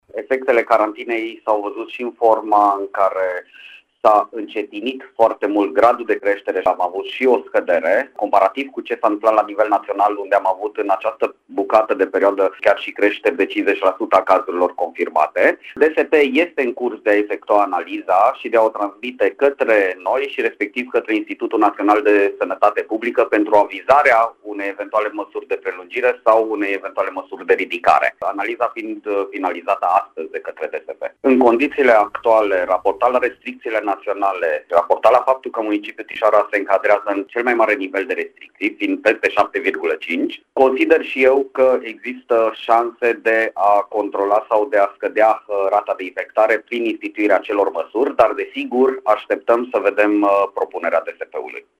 Prefectul de Timiș, Zoltan Nemeth, a apreciat, la Radio Timișoara, că în condițiile în care sunt în vigoare restricțiile pentru localitățile cu rata mară de infectare, nu ar mai fi necesară și carantina.